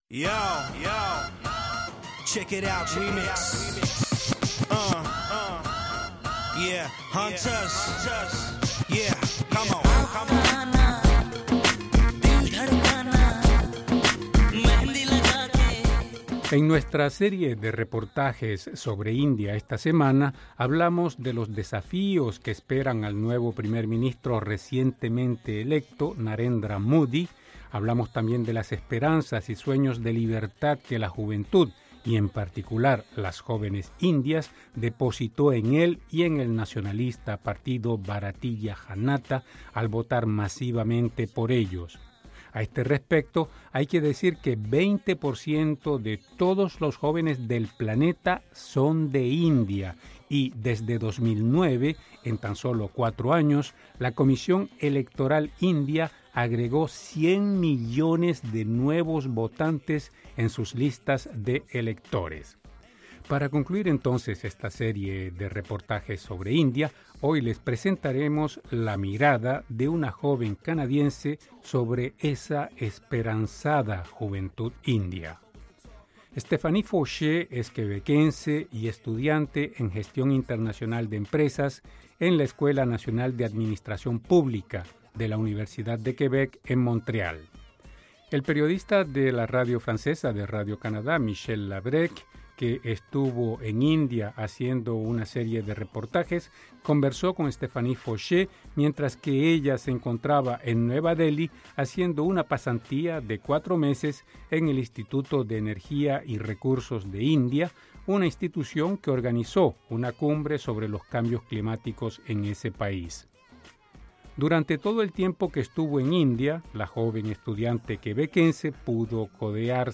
por | publicado en: Comunidad hindú, Reportajes | 0